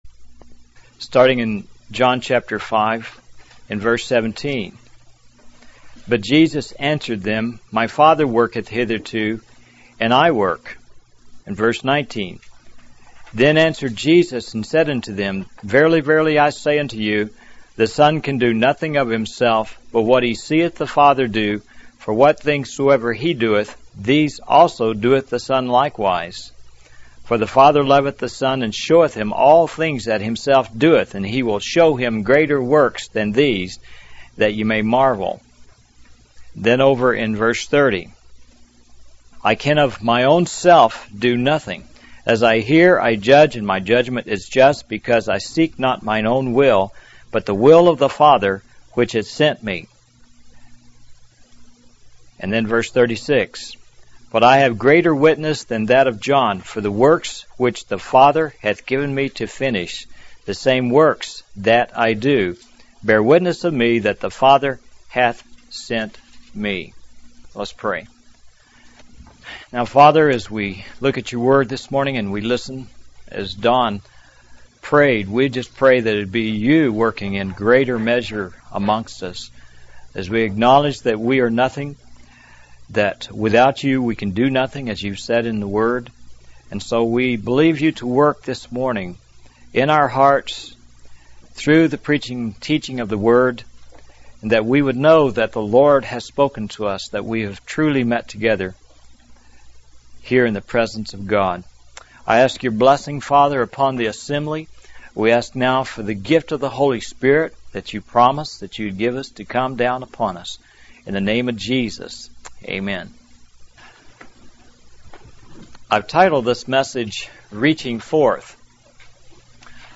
In this sermon, the preacher focuses on John chapter 5 and highlights it as a chapter with a special miracle.